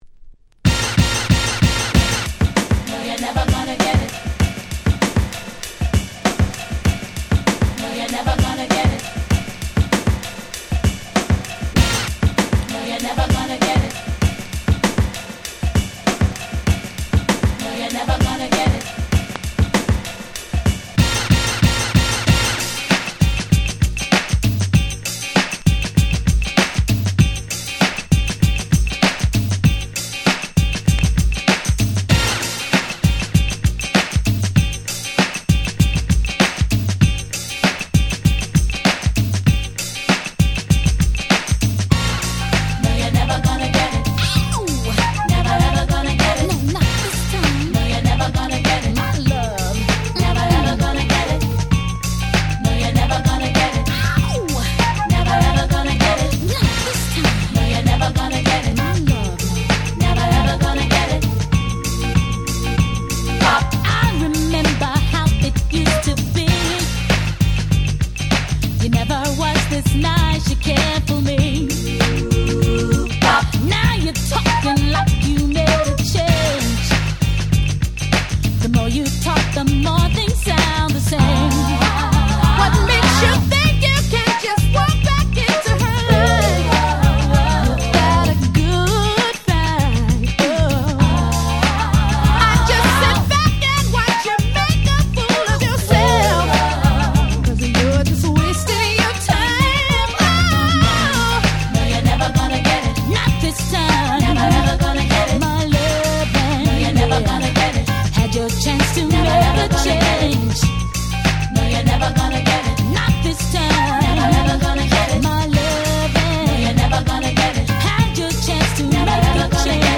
92' Super Hit R&B !!
彼女達らしい色っぽいダンスナンバー！